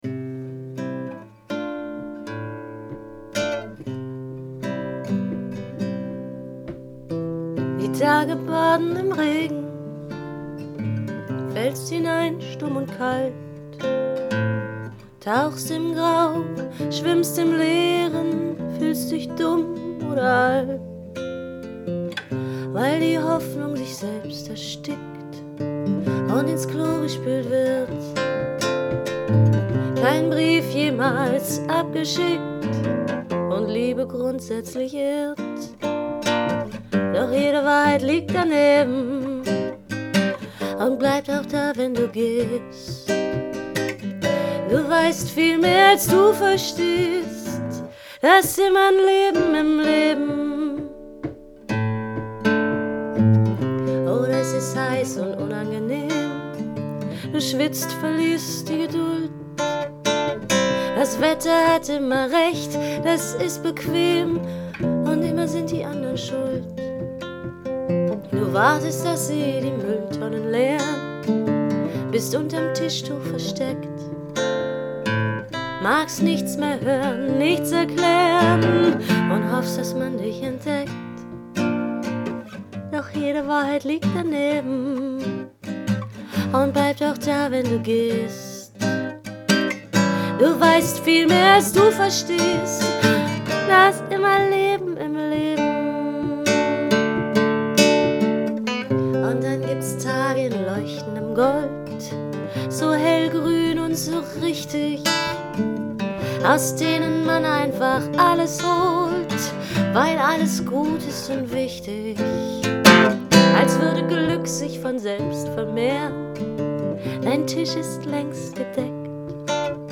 Da ist dir wirklich etwas sehr Feines gelungen auch textlich, du hast dich bei allem richtig entschieden und wunderbar gesungen.
Die Melodie und auch der Text irgendwie leicht, irgendwie ein wenig sentimental, ein wenig frech - klasse Mischung!